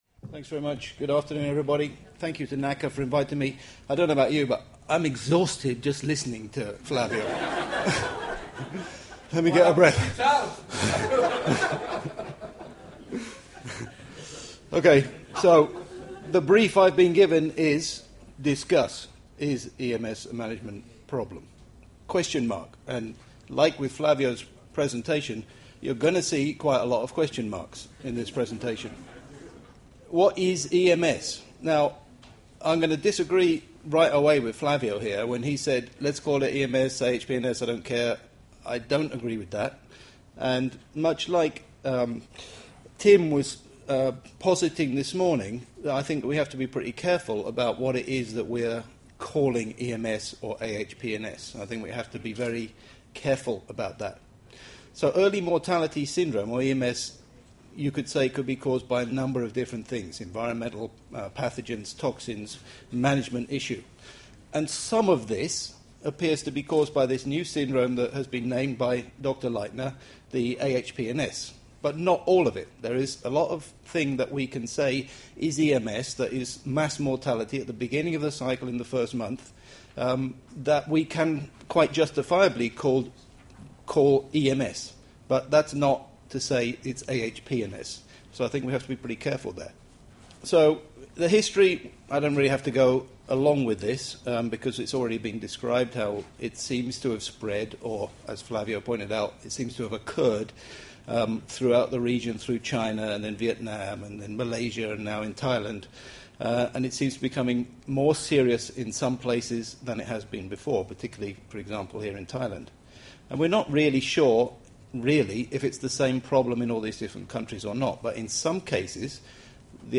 Presentation on the role of management in acute hepatopancreatic necrosis syndrome or "EMS".